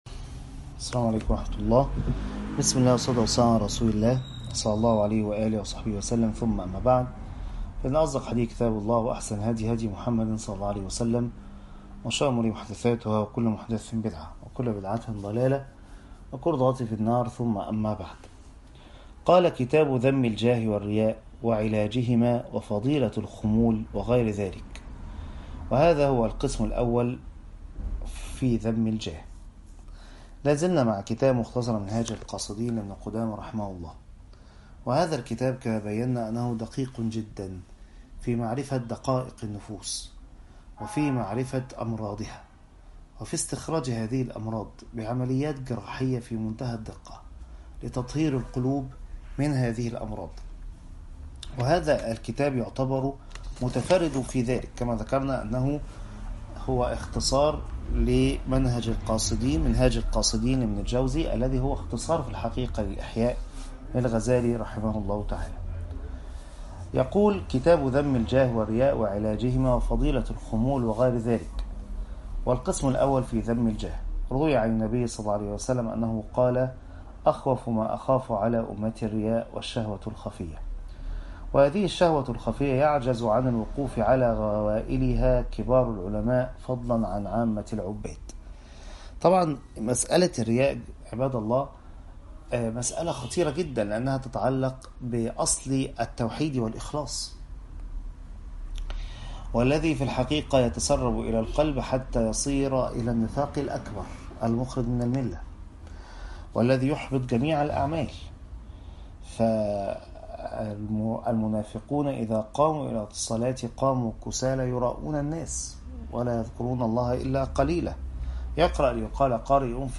الدرس الثاني عشر - دورة قلب سليم ♥